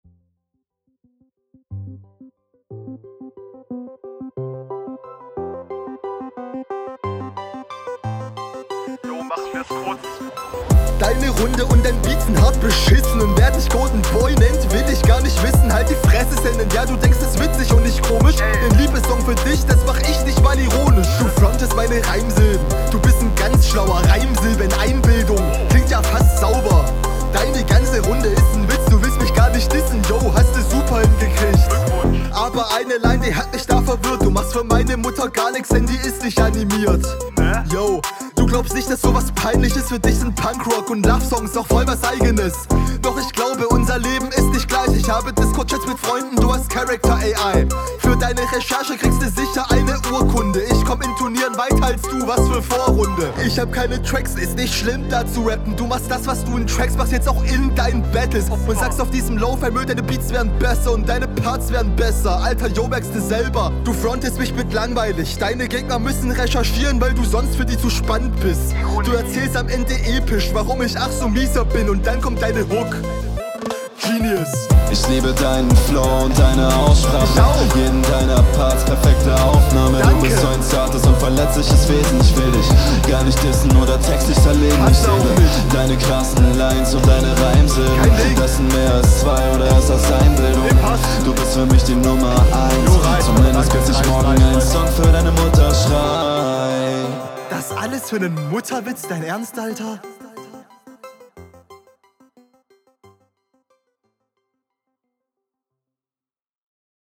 Flow kommt zwar offensichtlich ein ganzes Stück schlechter als beim Gegner wegen …